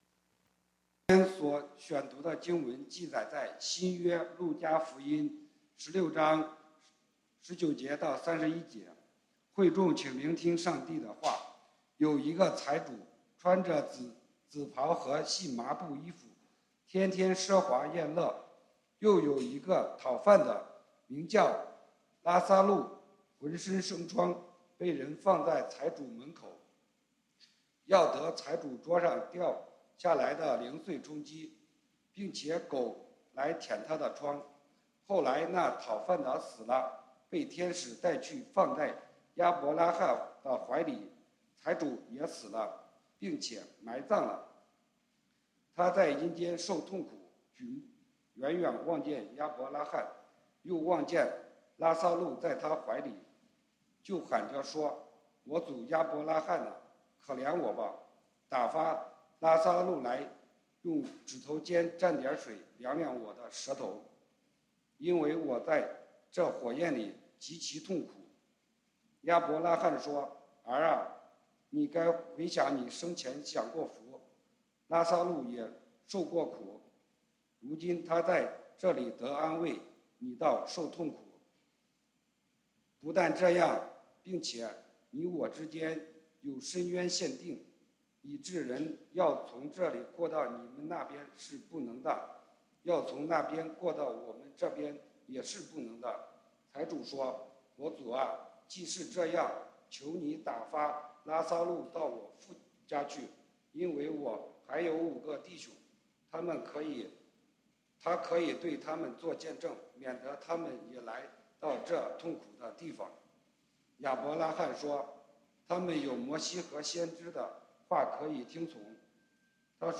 講道經文：路加福音 Luke 16:19-31 本週箴言：馬太福音 Matthew 16:26 耶穌說：「人若賺得全世界，賠上自己的生命，有甚麼益處呢？